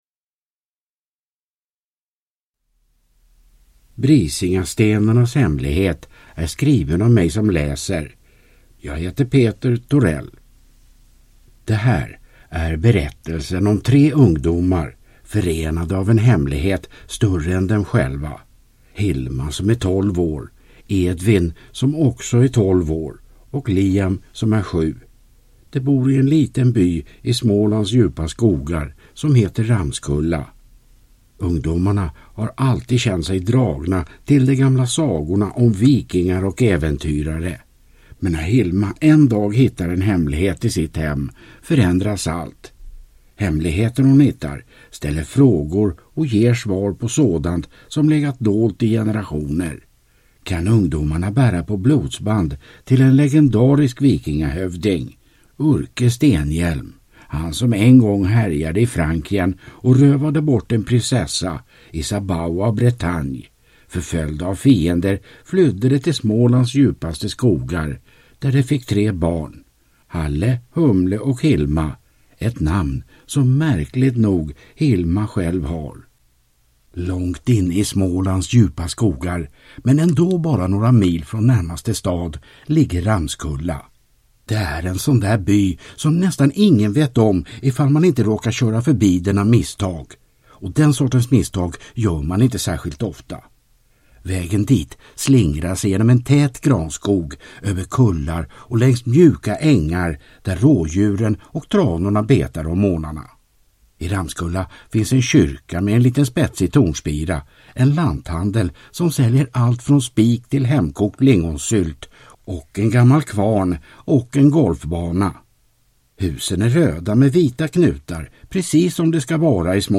Brisingastenarnas hemlighet – Ljudbok